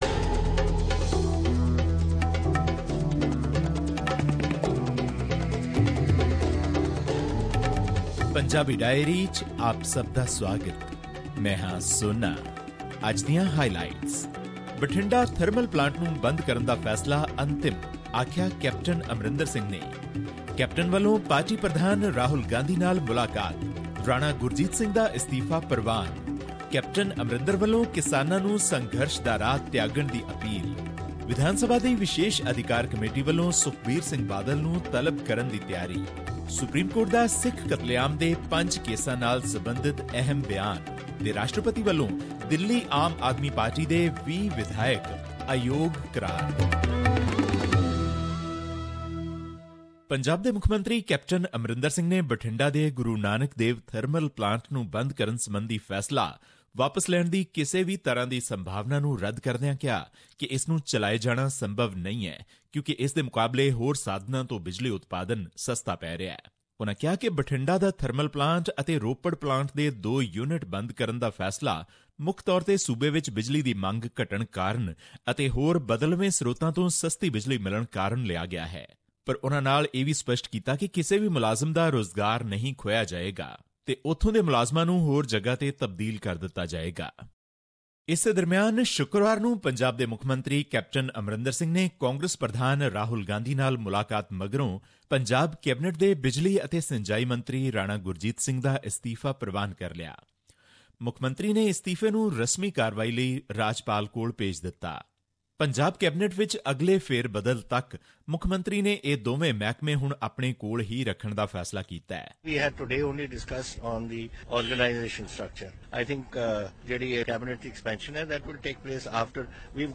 This report was presented on SBS Punjabi program on Jan 22, 2018, which touched upon issues of Punjabi and national significance in India.